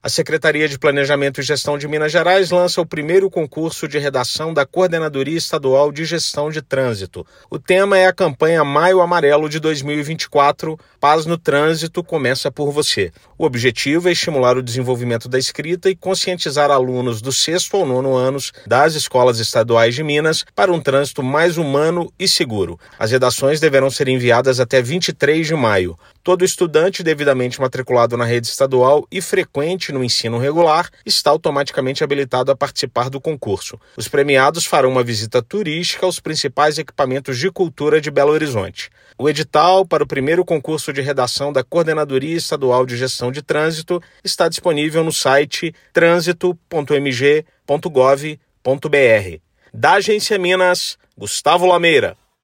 [RÁDIO] Seplag lança concurso de redação sobre o Maio Amarelo para estudantes do ensino fundamental
Ouça a mtéria de rádio.